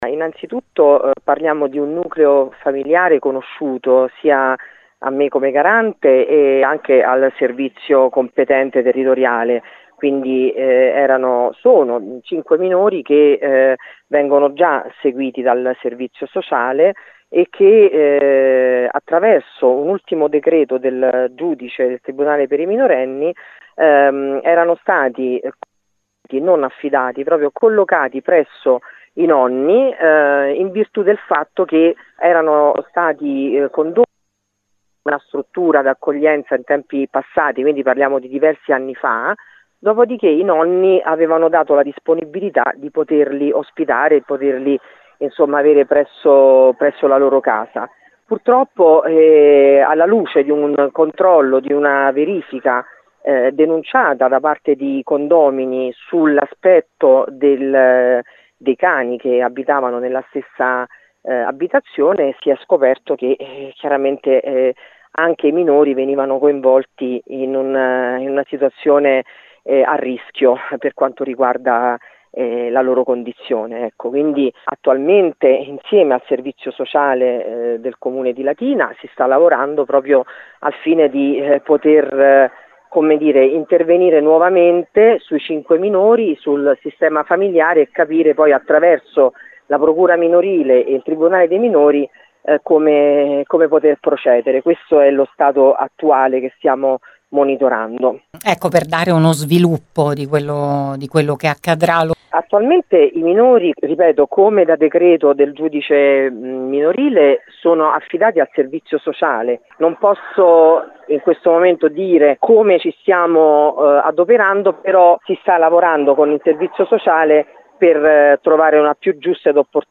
Così la Garante Sansoni ha illustrato la vicenda a Gr Latina